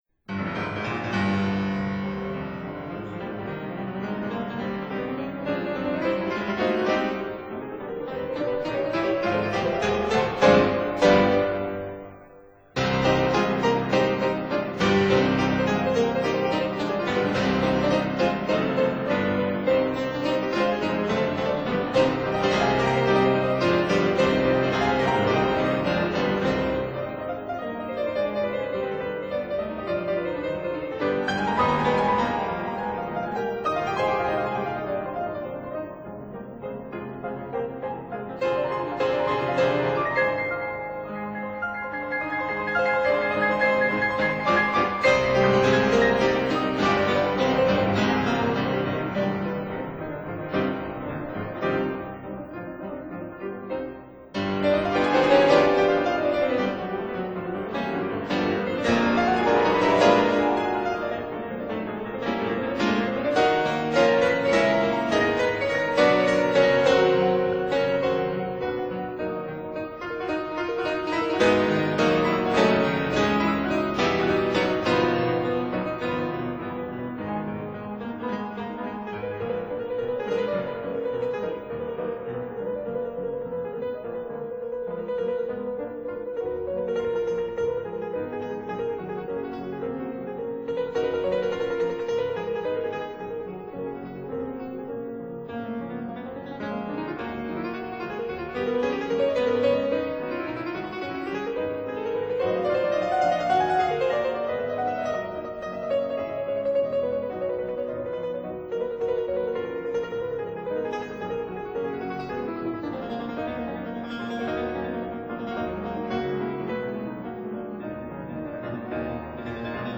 piano I
piano II